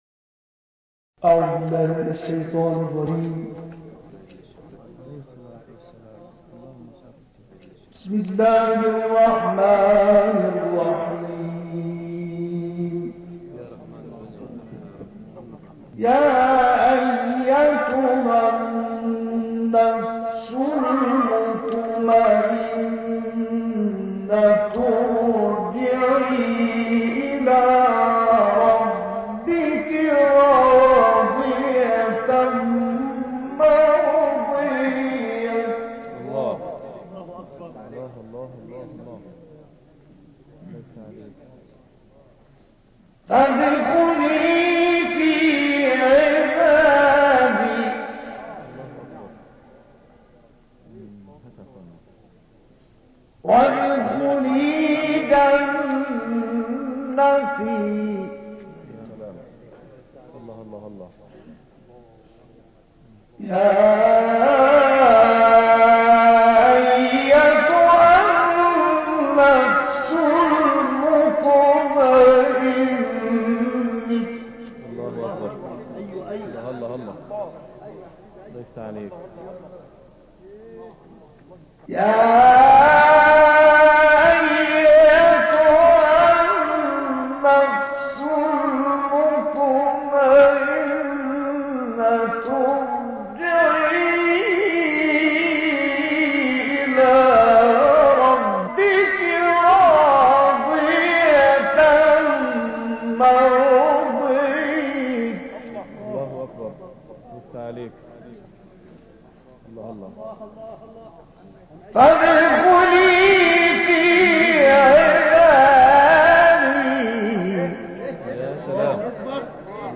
البته شیخ حصان در جوانی سبکی نو در تلاوت ابداع کرد و با ارائه روش‌هایی جدید در تنغیم محبوب‌تر شد.
در سومین روز از بهار ۱۴۰۰ تلاوتی شاهکار از این قاری فقید شامل آیات ۲۷ تا آخر سوره فجر و سوره‌های بلد، لیل، ضحی، شرح، تین و علق تقدیم می‌شود.